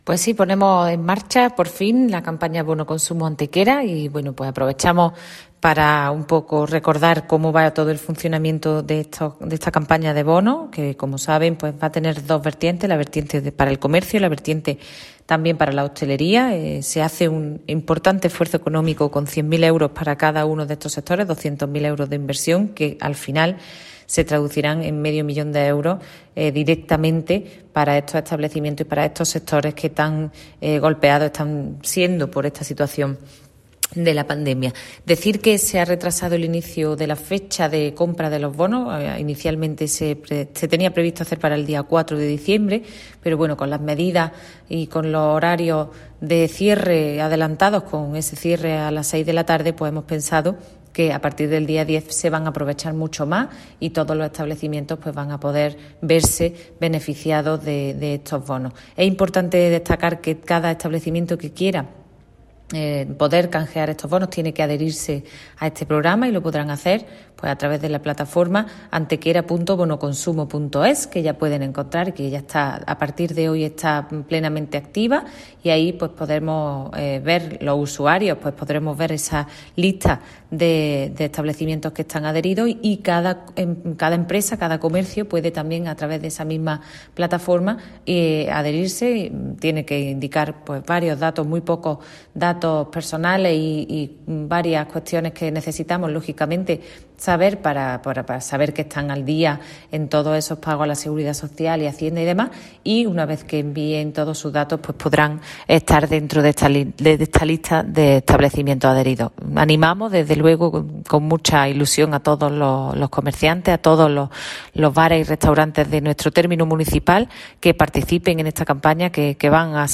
La teniente de alcalde de Turismo, Patrimonio Histórico, Políticas de Empleo y Comercio, Ana Cebrián, confirma que ya se ha habilitado el portal web en torno a las anunciadas campañas de Bono Comercio y Bono Hostelería con las que el Ayuntamiento apoyará y promoverá las compras en el comercio y la hostelería de nuestra ciudad durante la Campaña de Navidad a través de una inversión directa de 200.000 euros que, junto a los 300.000 añadidos directamente por los respectivos clientes, pretende inyectar en estos sectores medio millón de euros como medida para hacer frente a la crisis económica derivada de la pandemia del coronavirus.
Cortes de voz